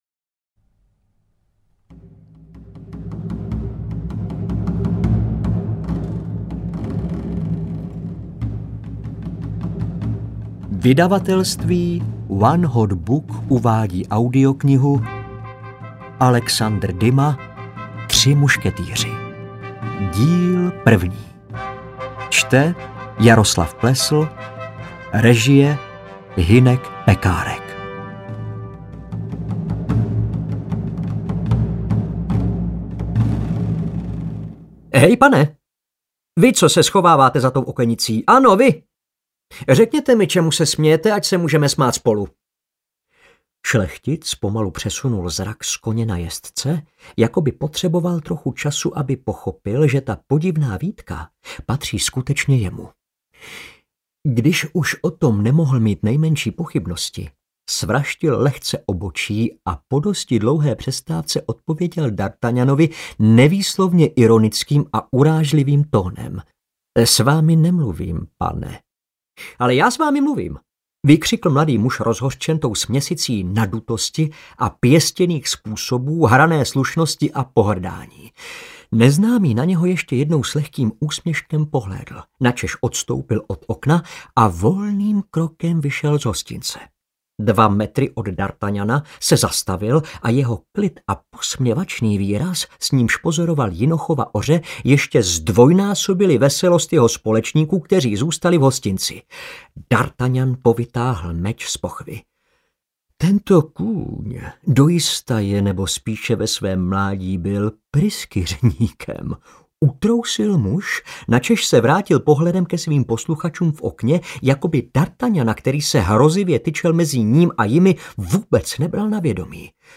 Tři mušketýři I. audiokniha
Ukázka z knihy
• InterpretJaroslav Plesl